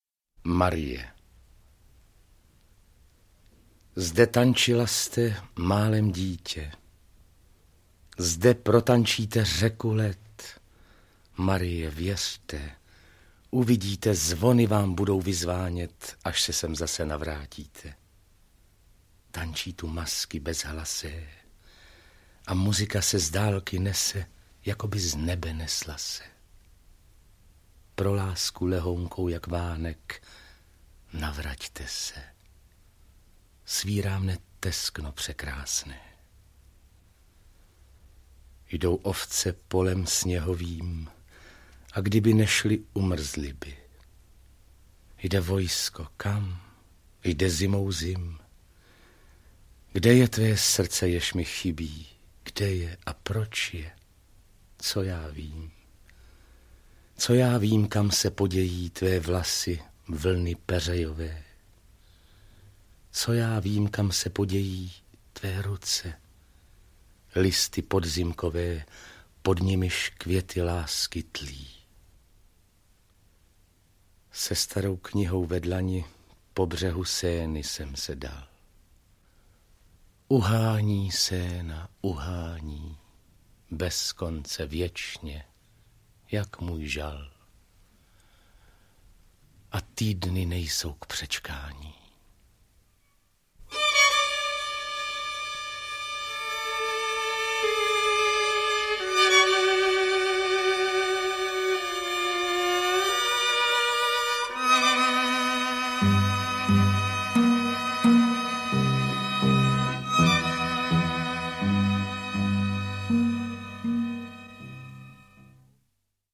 Básně Guillauma Apollinaira audiokniha